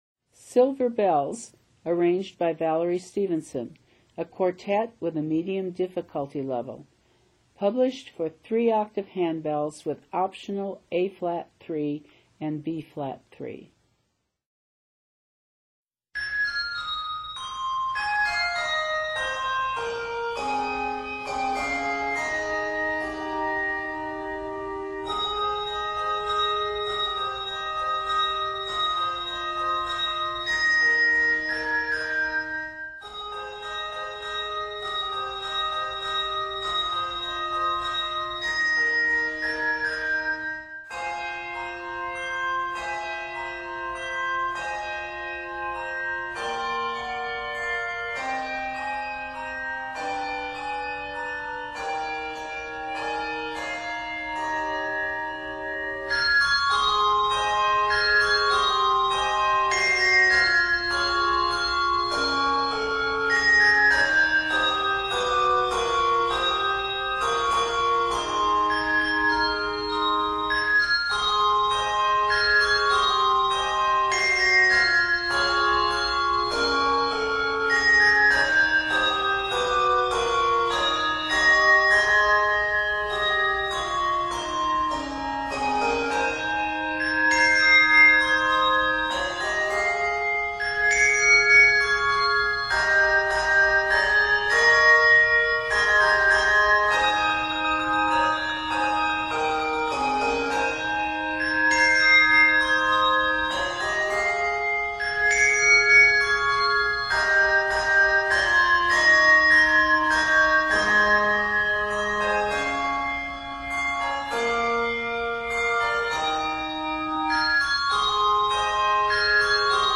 Quartet